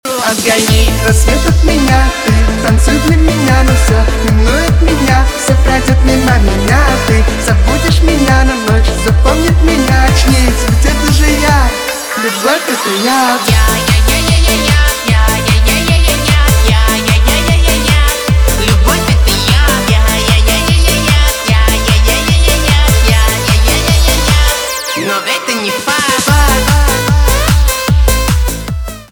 поп
танцевальные